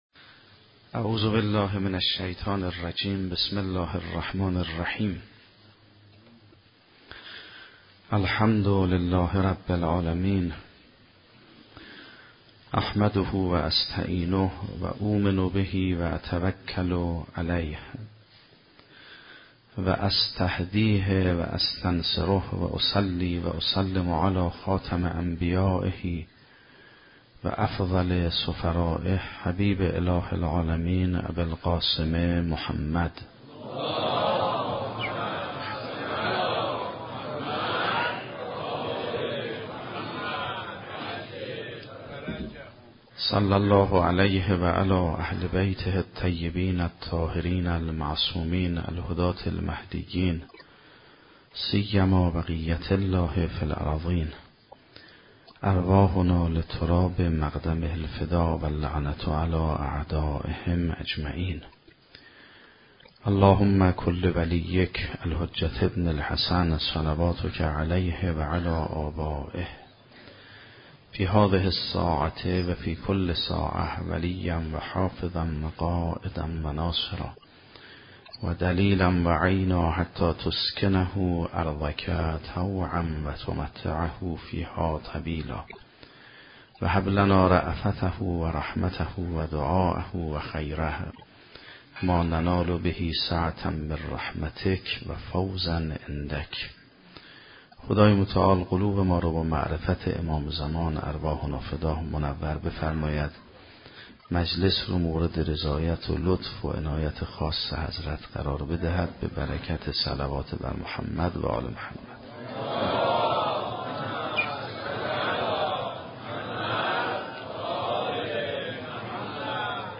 سخنرانی آیت الله سیدمحمدمهدی میرباقری در دهه اول محرم 96